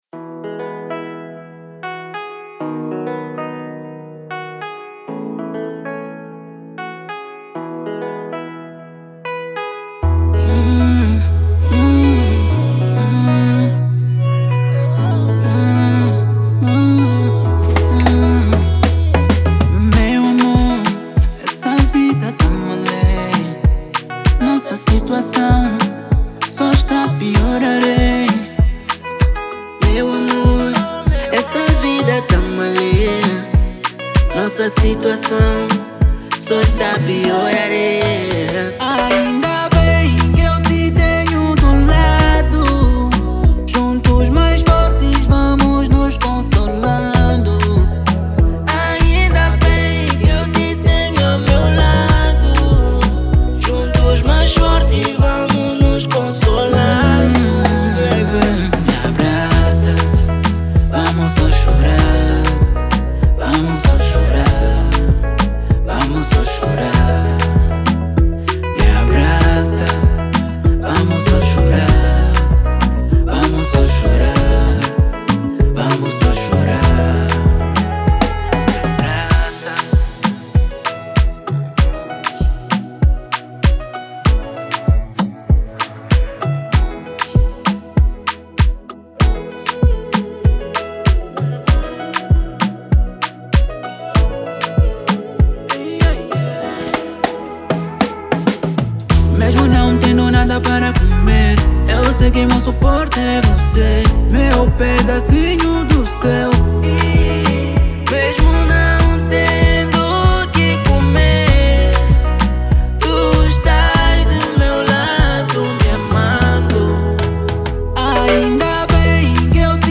Kizomba
2023 Afrobeat Mp3 Download